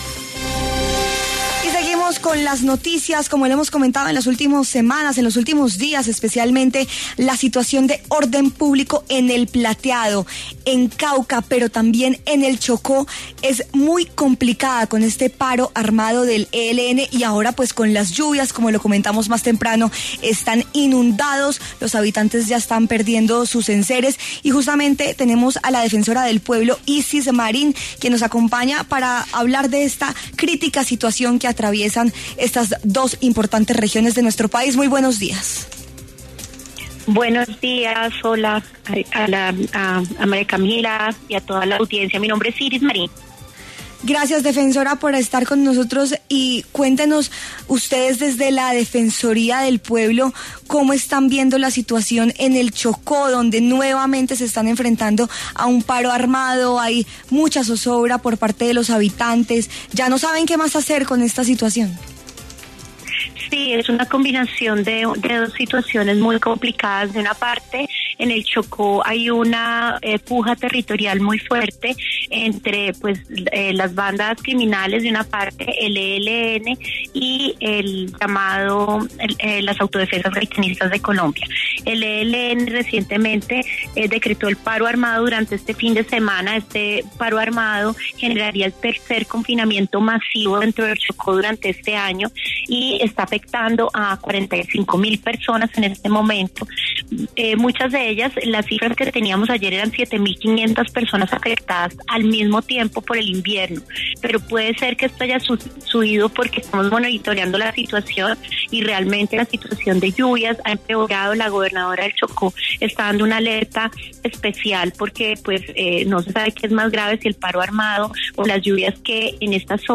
Iris Marín, defensora del Pueblo de Colombia, pasó por los micrófonos de W Fin De Semana para hablar sobre el atentado de las disidencias de las Farc en El Plateado, corregimiento de Argelia, Cauca, y que dejó un soldado muerto y otro más herido.